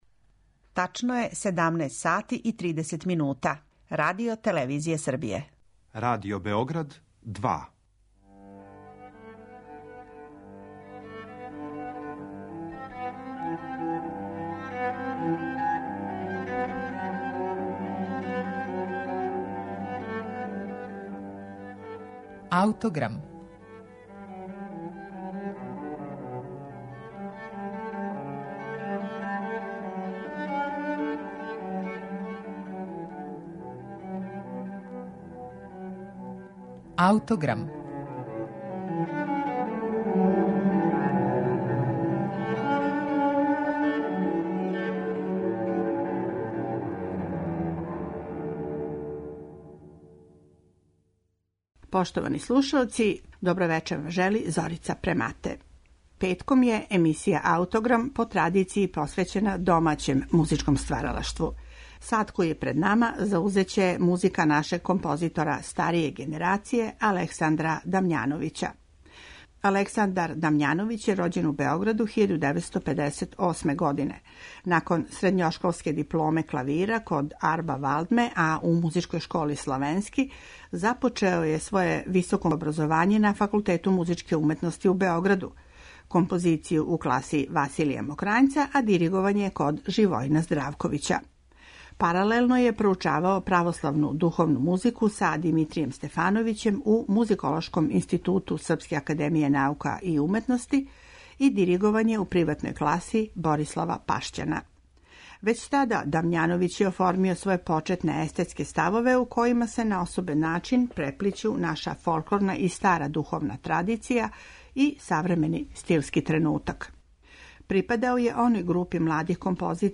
Емисију ће отворити његова „Поема" за виолину и оркестар.